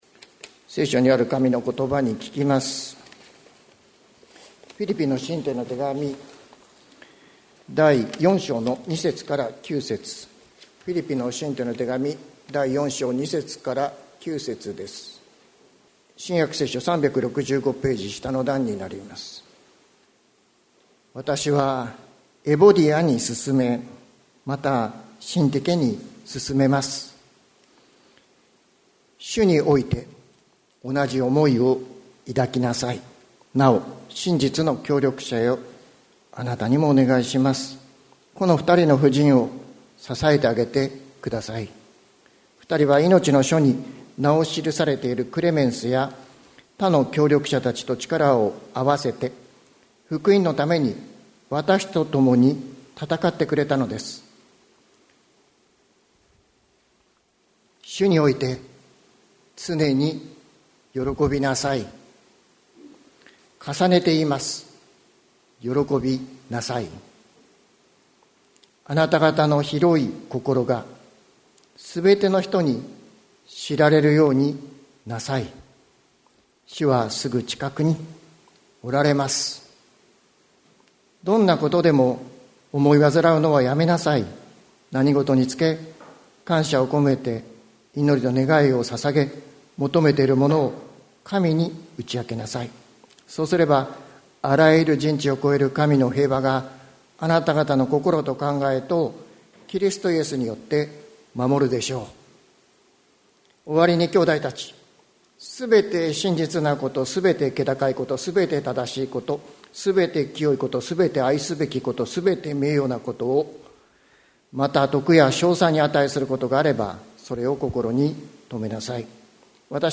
2024年07月07日朝の礼拝「人知を超える神の平和」関キリスト教会
説教アーカイブ。